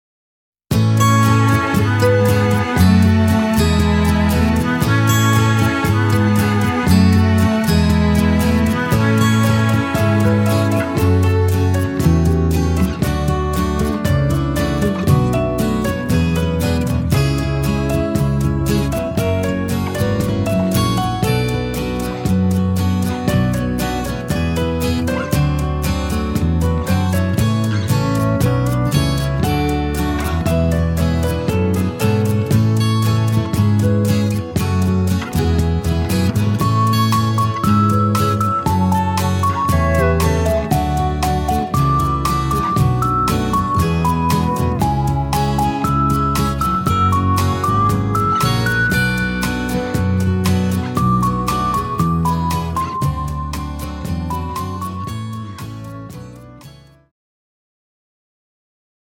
Foxtrot